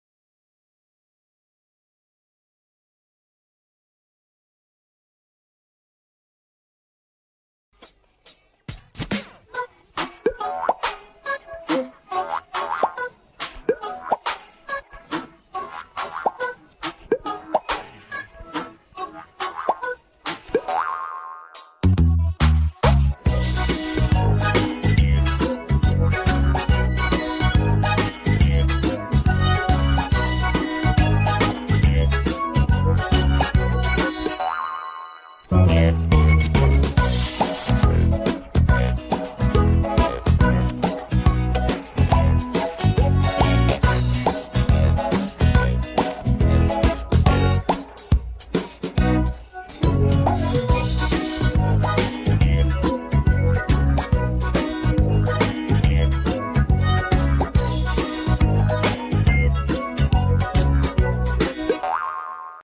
• Теги: минусовка
Минусовка (мастер задавка).